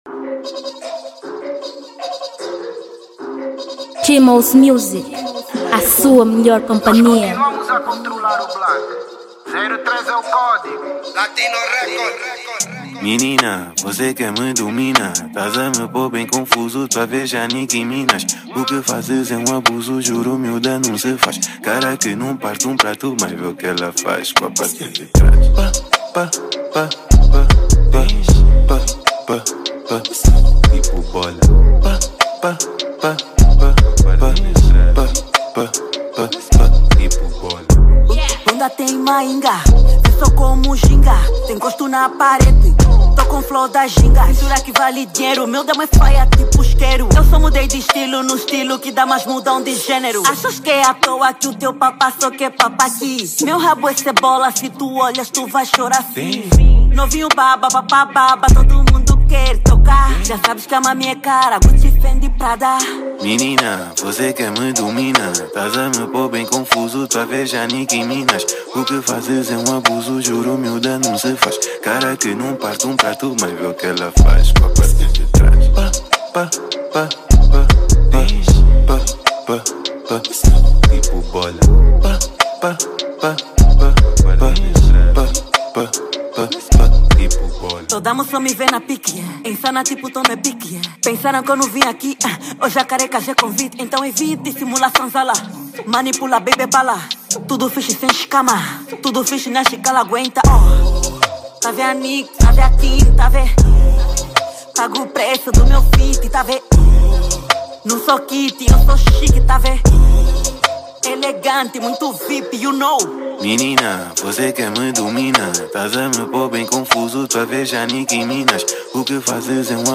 Uma música do gênero Rap com um bom conteúdo.
Gênero: Rap